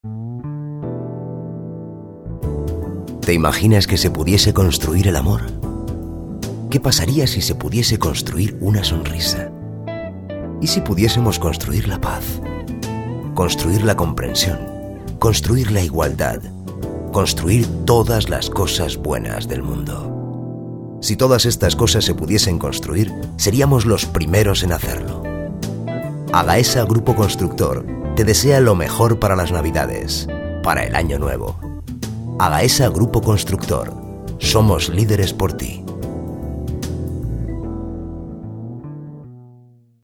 Spanish Voice Over .